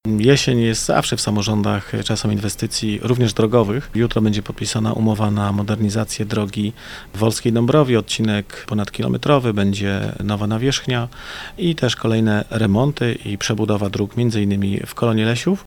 Wójt Gminy Jastrzębia dodaje: